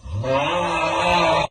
snore-3.ogg